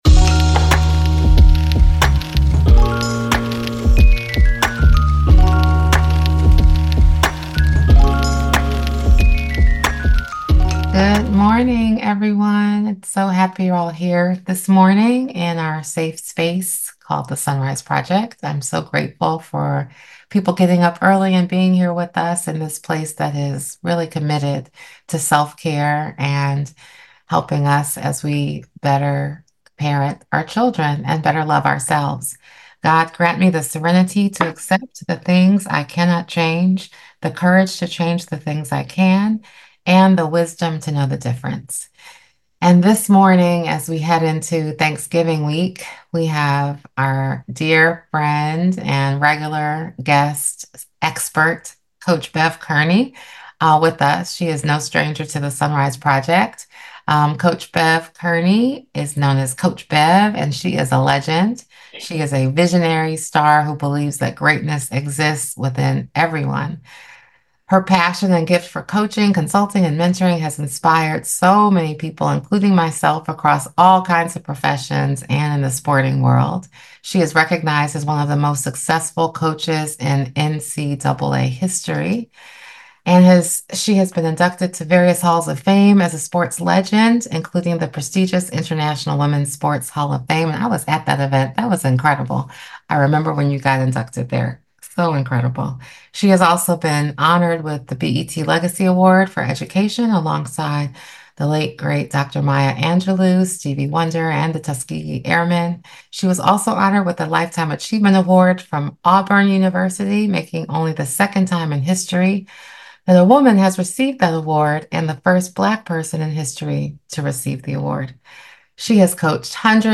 Don't miss this uplifting conversation on finding peace and purpose, no matter what life throws your way.